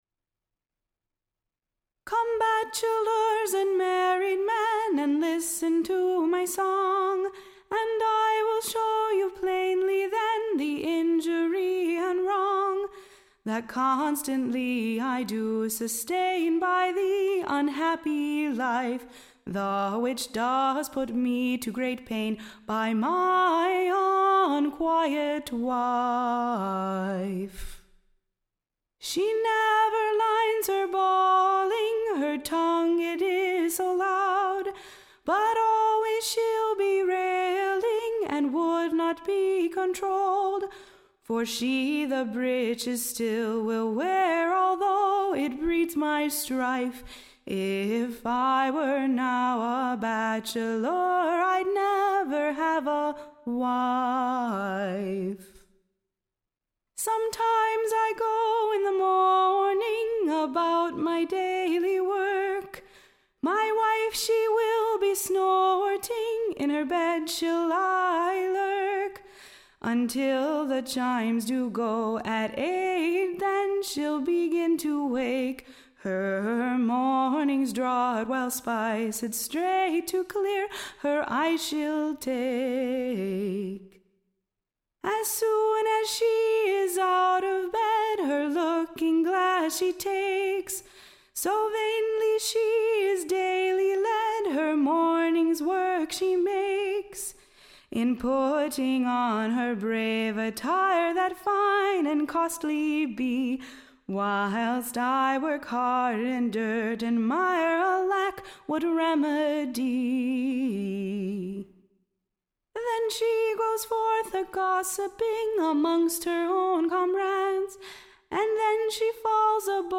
Recording Information Ballad Title The Cruell Shrow: / OR, / The Patient Mans VVoe. / Declaring the misery, and the great paine, / By his vnquiet wife he doth dayly sustaine.